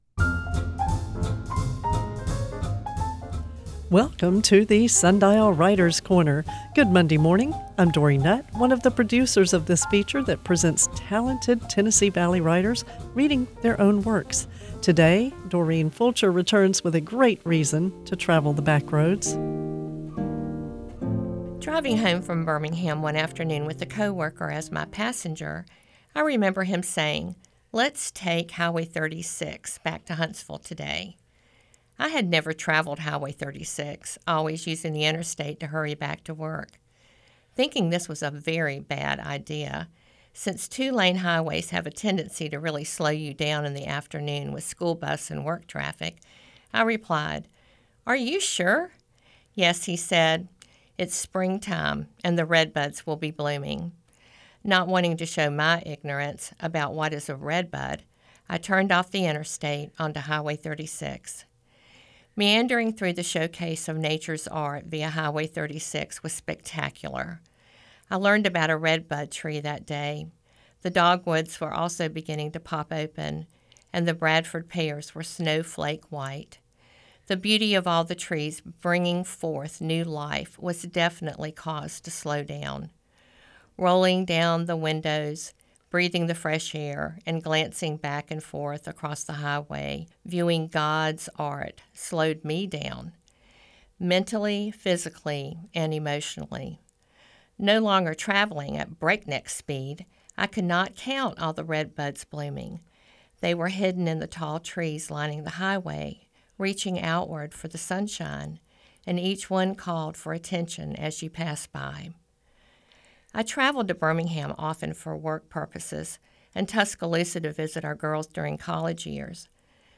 The Writer’s Corner on WLRH features prose and poetry submitted by talented TN Valley wordsmiths. With each episode, a different voice is heard in “The Writer’s Corner,” with a commentary or poem recorded by the author.